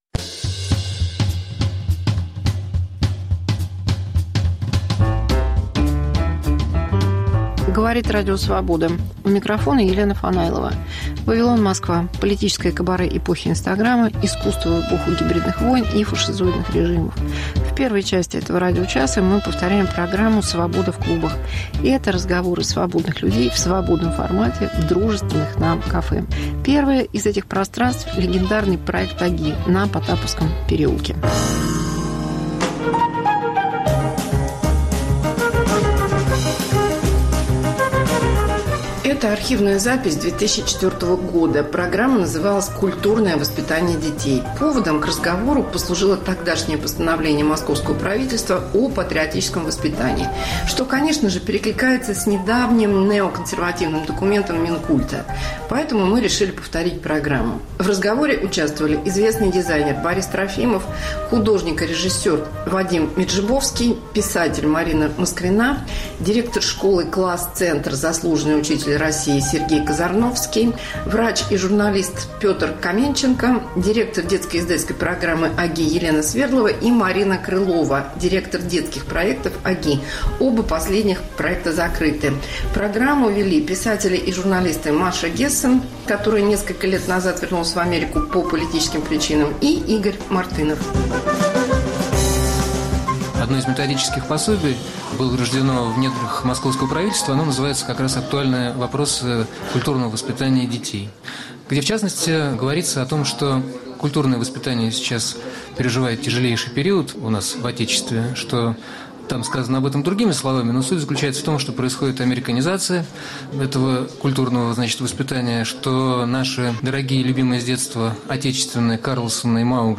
Мегаполис Москва как Радио Вавилон: современный звук, неожиданные сюжеты, разные голоса. 1. Из архива 2004: терроризм в России, правомерность "исламского террора" в стране. 2. Эпидемия COVID-19 c политической и философской точки зрения.